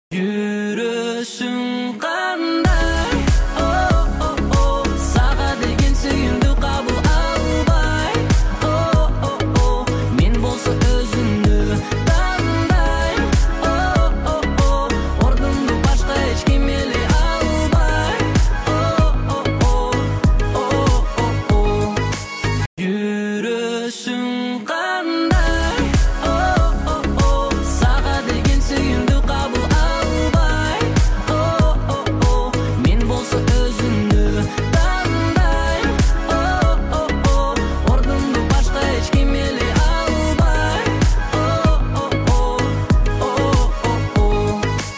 Скачать музыку / Музон / Казахская музыка 2024